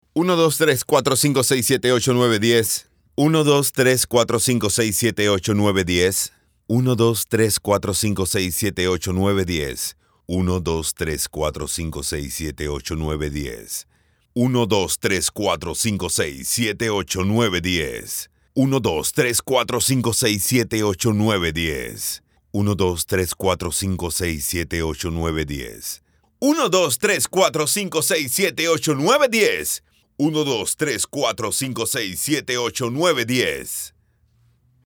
Voz diferente, juvenil y profunda.
Sprechprobe: Sonstiges (Muttersprache):